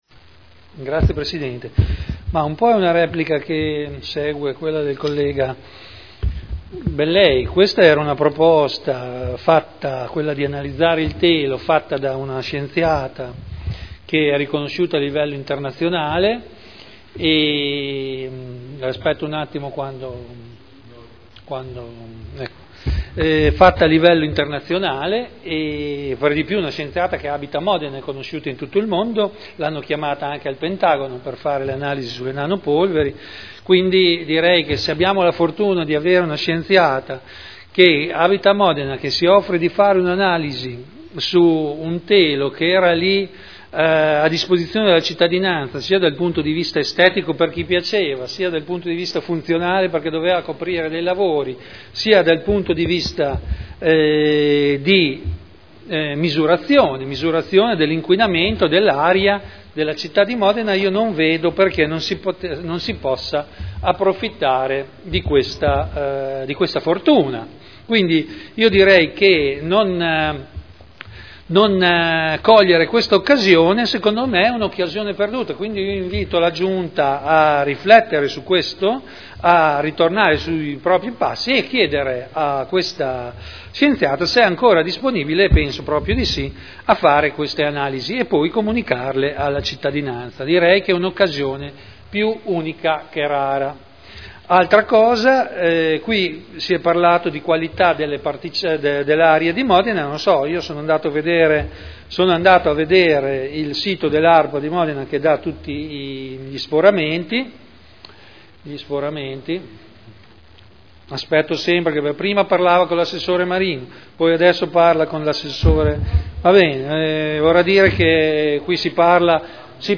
Vittorio Ballestrazzi — Sito Audio Consiglio Comunale